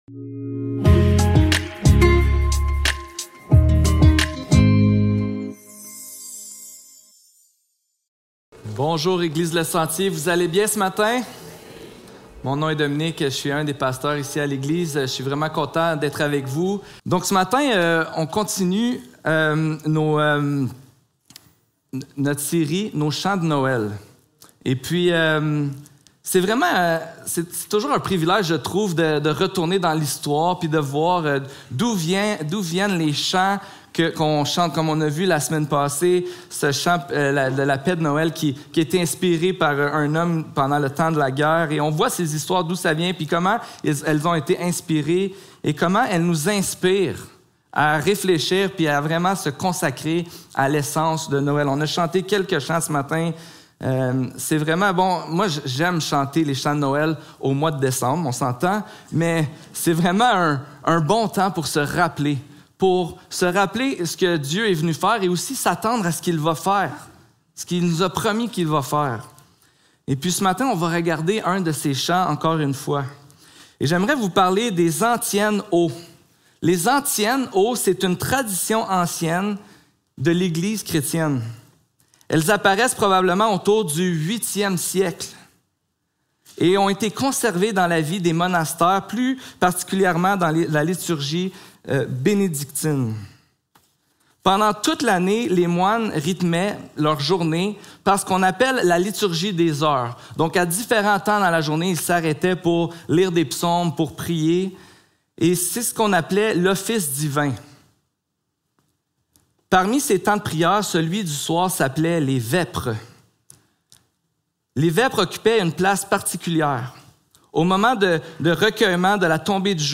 Matthieu 1.18-25 Service Type: Célébration dimanche matin Description